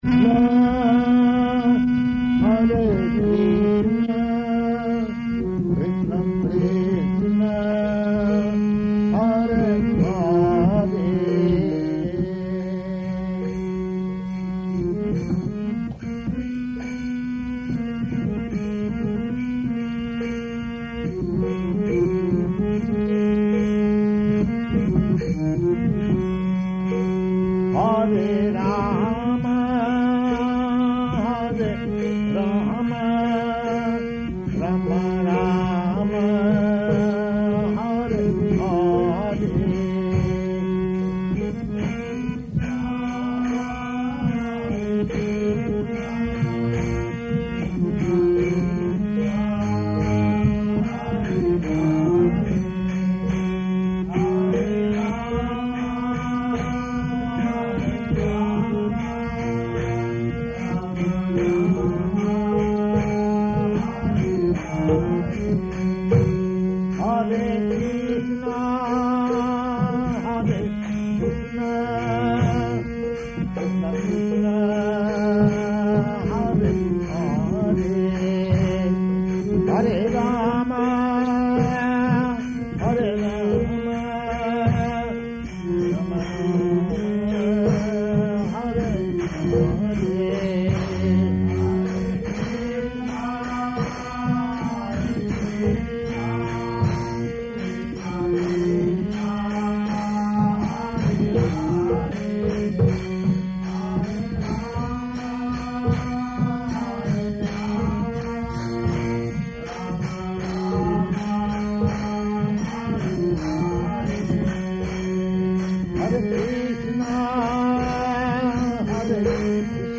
Hare Kṛṣṇa Bhajan --:-- --:-- Type: Lectures and Addresses Dated: March 20th 1969 Location: Hawaii Audio file: 690320BJ-HAWAII.mp3 Prabhupāda: [sings Hare Kṛṣṇa] [ prema-dhvani ] All glories to the assembled devotees.